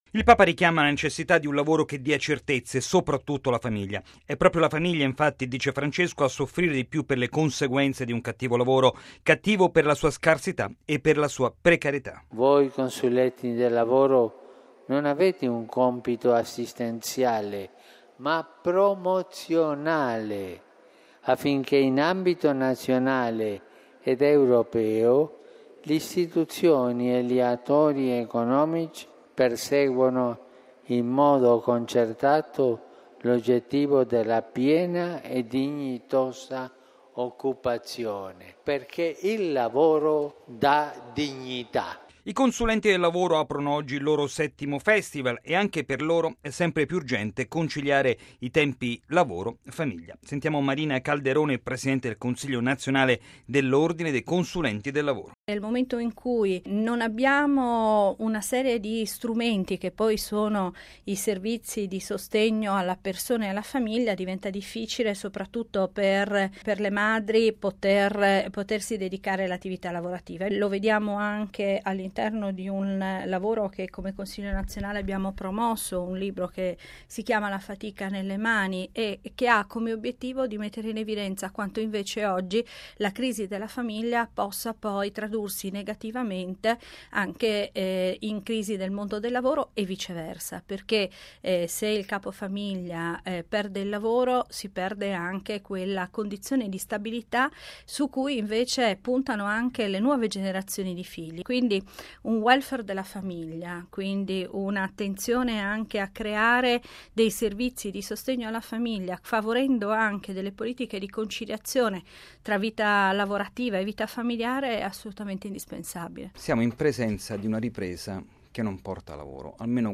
Bollettino Radiogiornale del 30/06/2016